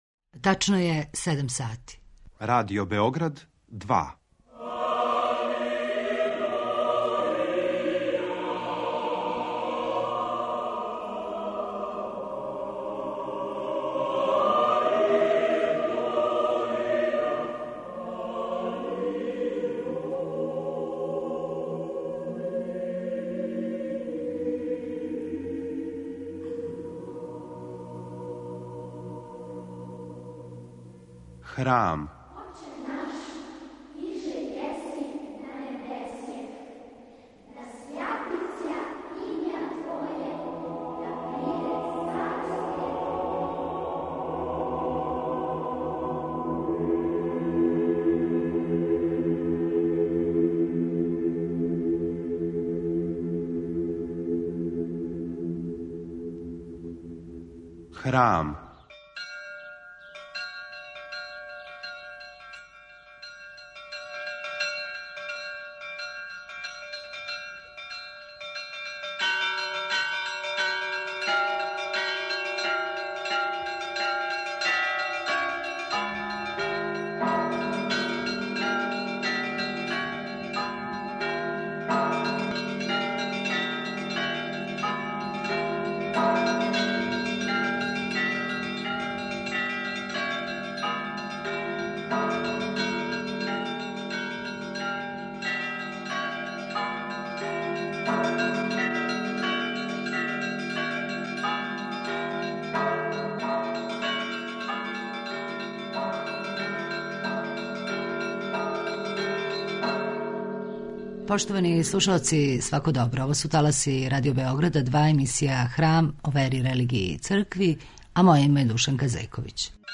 Гост у студију теолог